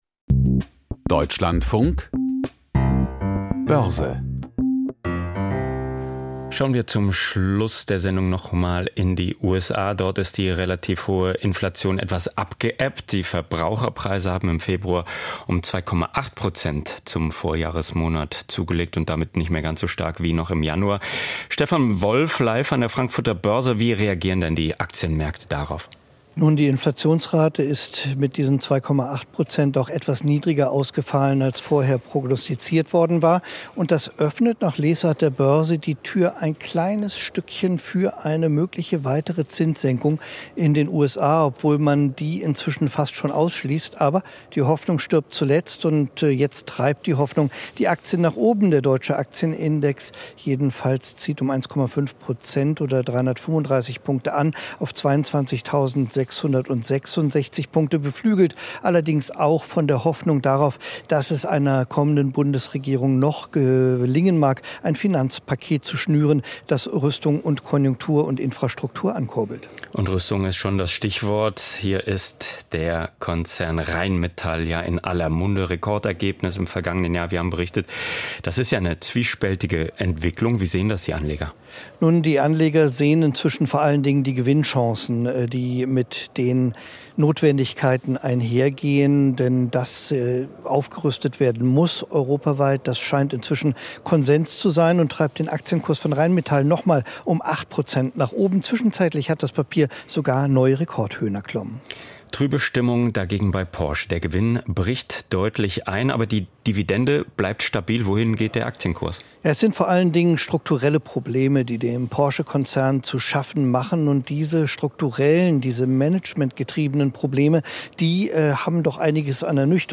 Börsenbericht aus Frankfurt a.M.